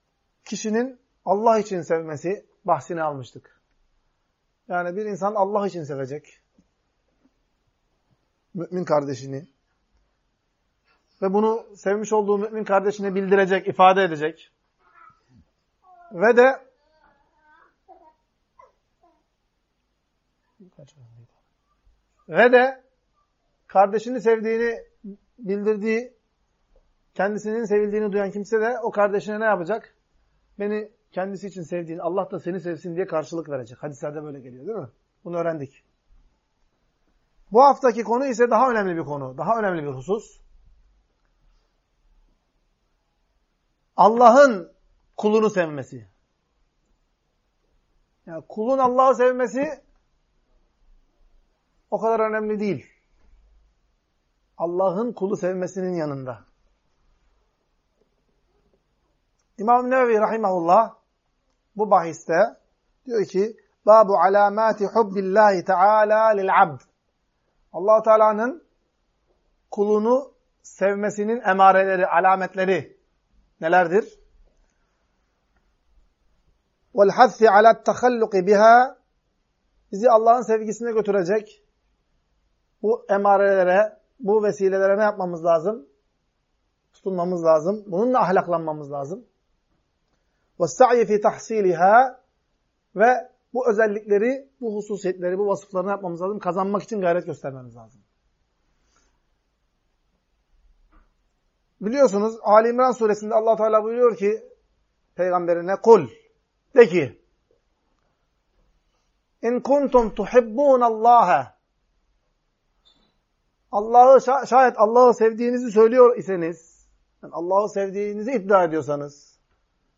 Ders - 47. Bölüm – Allah’ın Bir Kulun Sevdiğinin Alametleri, Bu Alamet Ve Özelliklere Sahip Olmaya Gayret Etmeye Teşvik